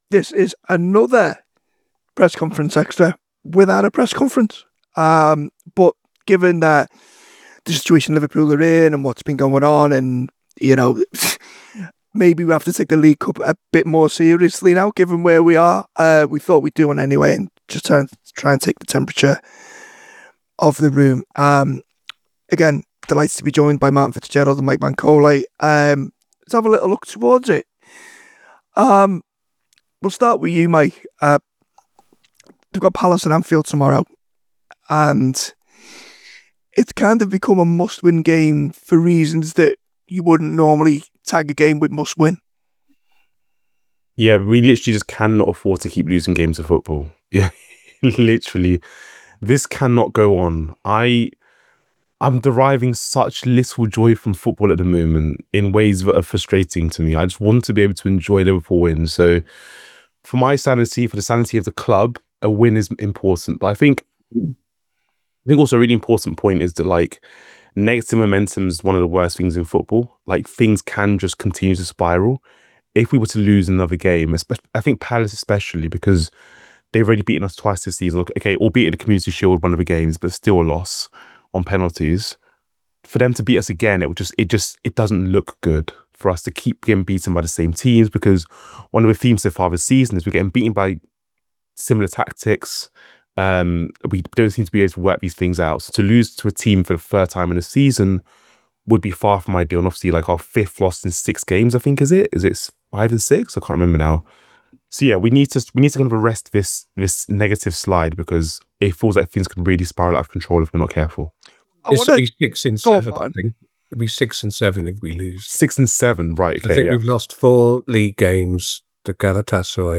Below is a clip from the show – subscribe for more on the Liverpool v Crystal Palace press conference…